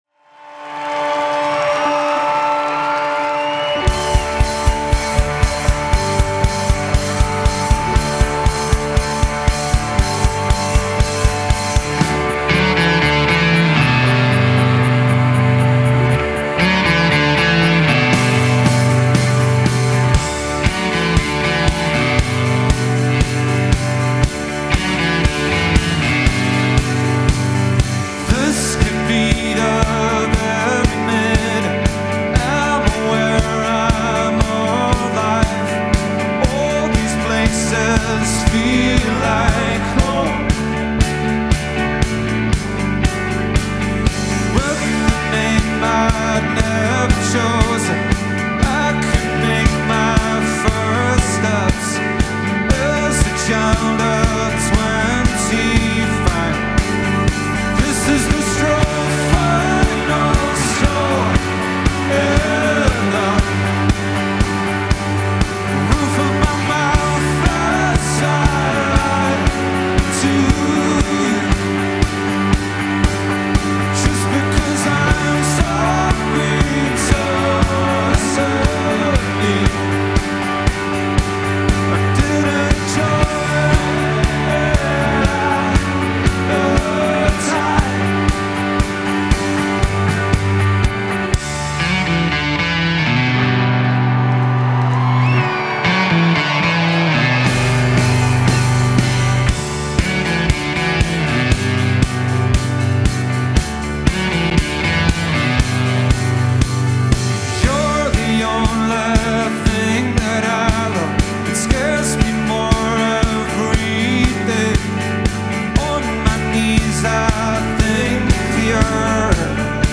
vocals, guitar
piano, guitar, keyboards, backing vocals